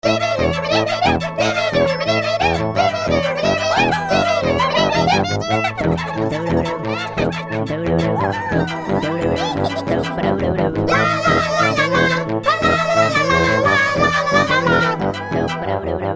• Качество: 128, Stereo
веселые